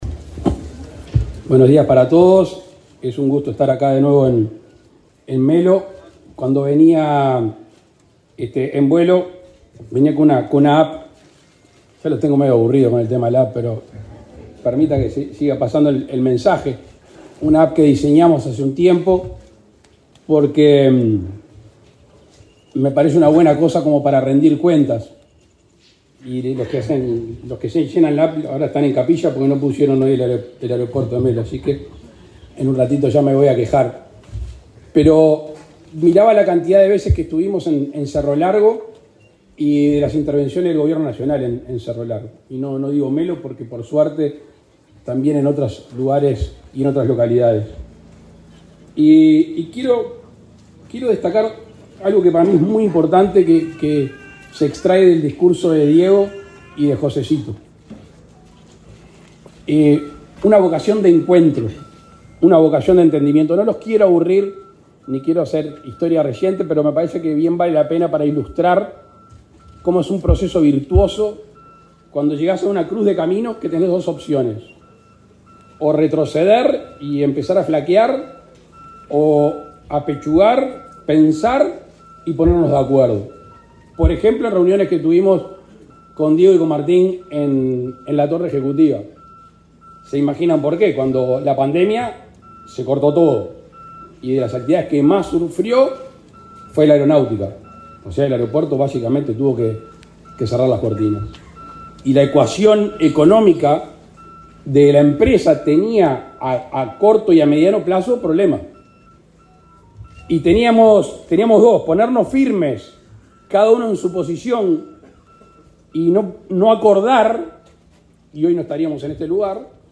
Palabras del presidente Luis Lacalle Pou
Palabras del presidente Luis Lacalle Pou 15/10/2024 Compartir Facebook X Copiar enlace WhatsApp LinkedIn El presidente Luis Lacalle Pou participó, este martes 15, de la inauguración del nuevo aeropuerto internacional de Melo, en el departamento de Cerro Largo.